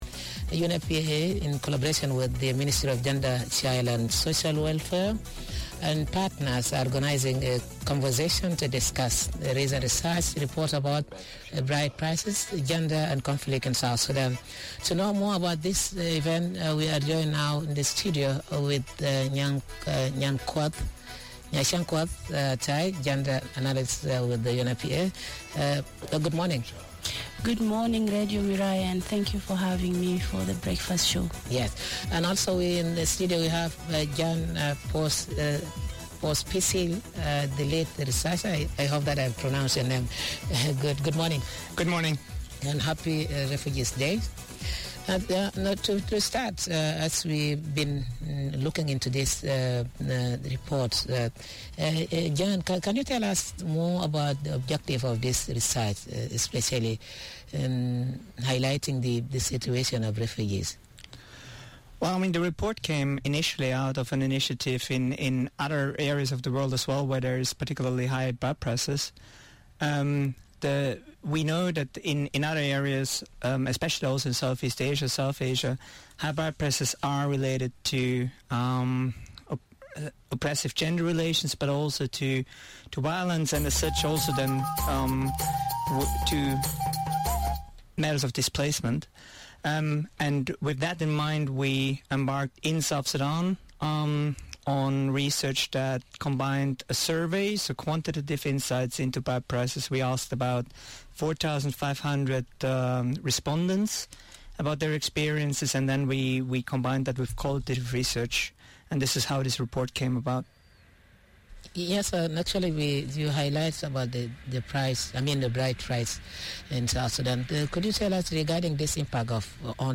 Bride price is South Sudan is tied to long existing cultures and customs of the diverse communities. However, the issue is much more complex as there are negative practices that emerge from the way in which marriage, bride price and entitlement tend to affect women within marital arrangements. UNFPA working with the Ministry of Gender, Child and Social Welfare are having a conversation on a research project that brings out insightful perspectives on pride price in South Sudan.